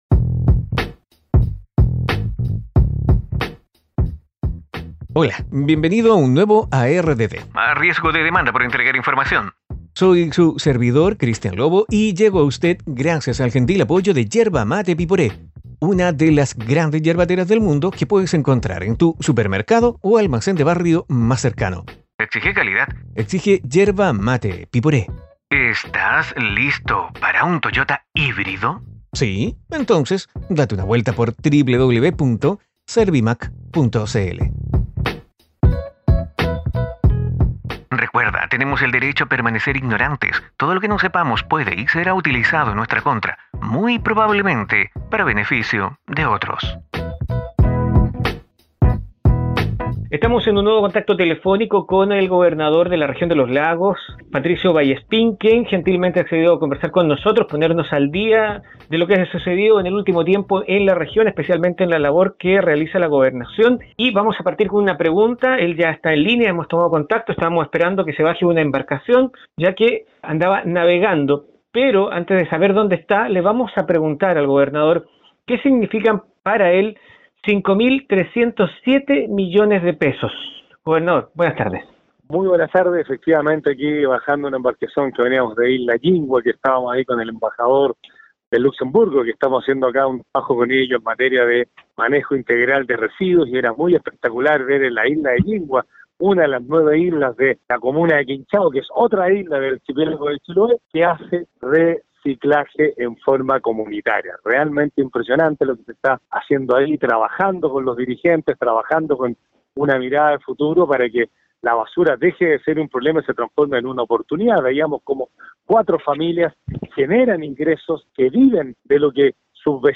En este "A riesgo de demanda" (ARDD) cambiamos en algo nuestro habitual estilo para tomar contacto con el Gobernador de la Región de Los Lagos, Patricio Vallespin, para ponernos al día en su labor: Reposiciones, Basuras, Escuelas, Ayudas, Tránsito, nombramientos de autoridades...